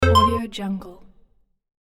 دانلود افکت صدای رابط کوتاه استاندارد
16-Bit Stereo, 44.1 kHz